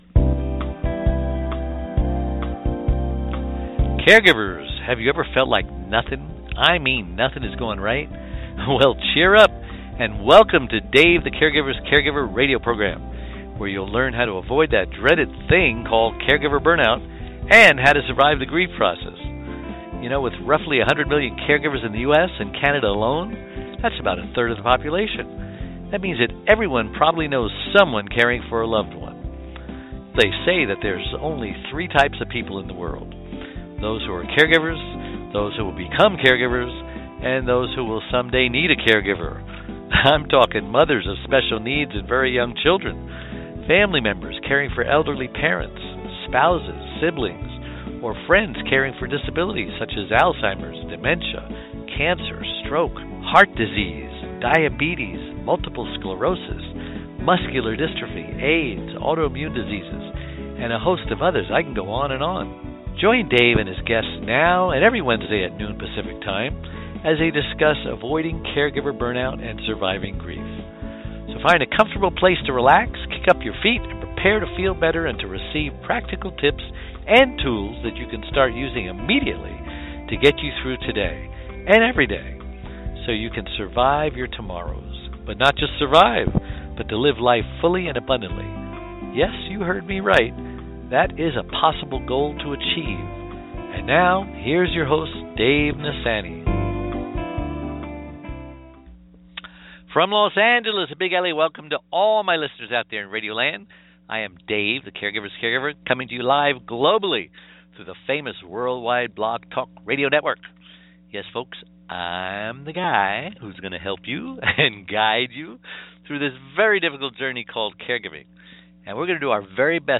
INTERVIEW+JOAN+BORYSENKO.mp3